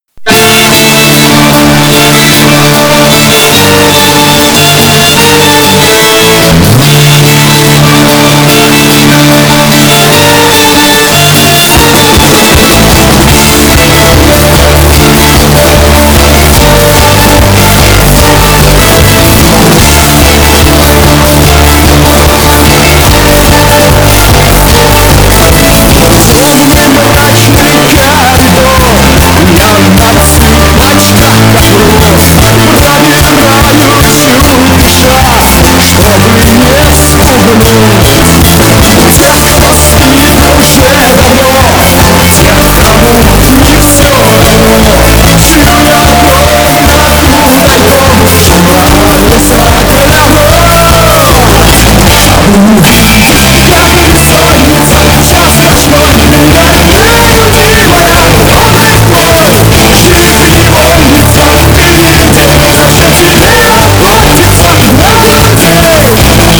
мужской вокал
веселые
виолончель
русский рок
очень громкие